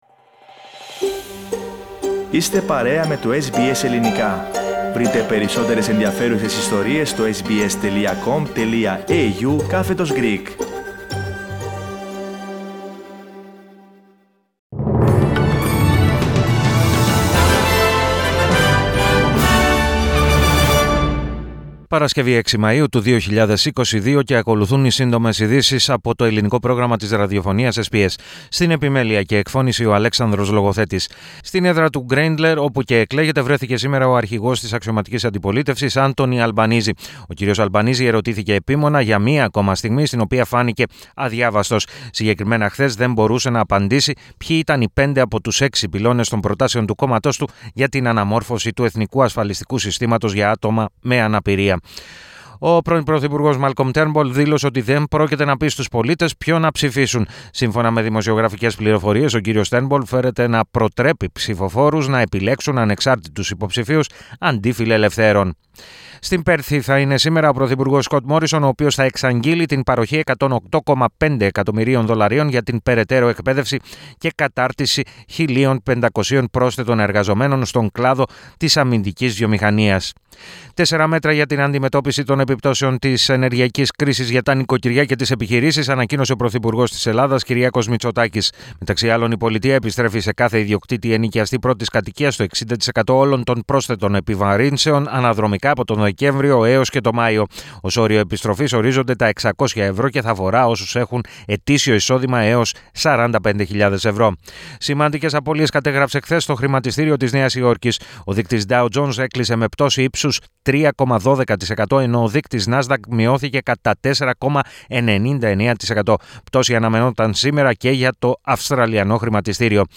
News in brief - Σύντομες ειδήσεις 06.05.22